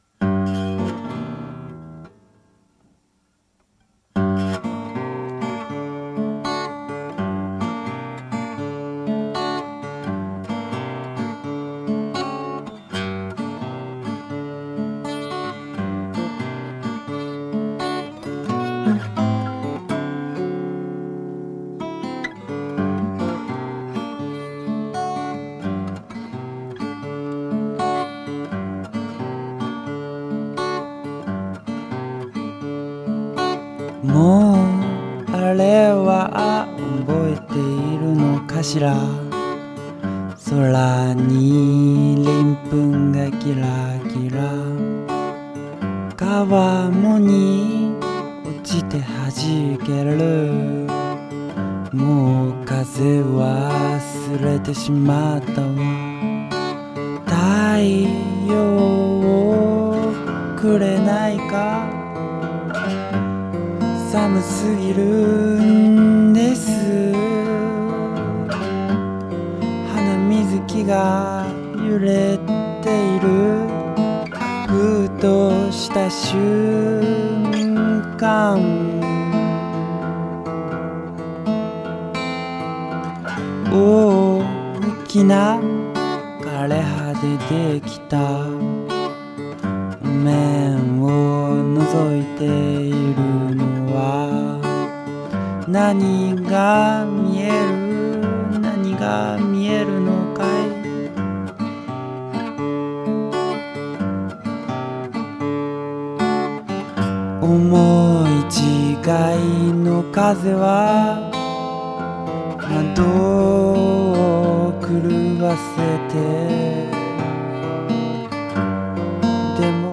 サックスやドラム、ピアノ等を取り入れた仄暗いアシッド・フォーク！
ザラついたギターの音色が、全体に漂う仄暗さをより際立たせている様にも感じられますね！